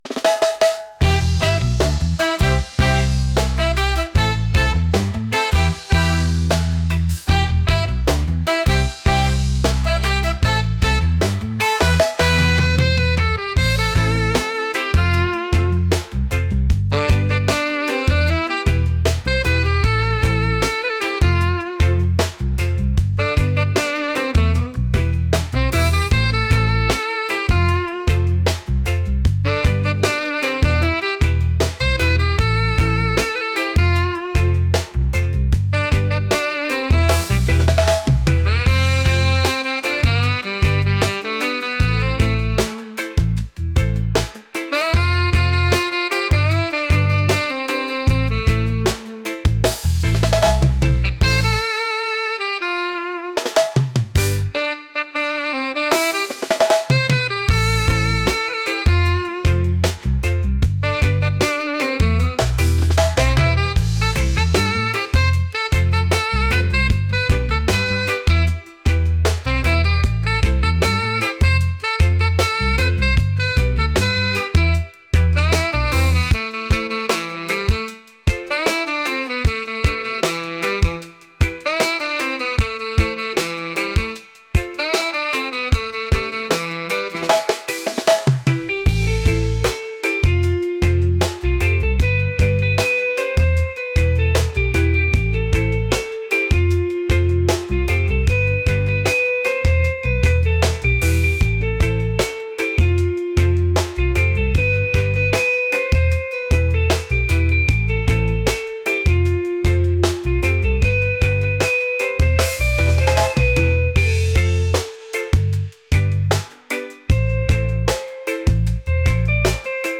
soul | upbeat | reggae